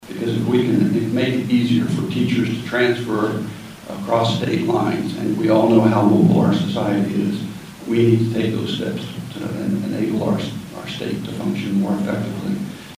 MANHATTAN — Hurdles to Medicaid expansion were a major highlight of Saturday’s legislative coffee held at the Sunset Zoo Nature Exploration Place.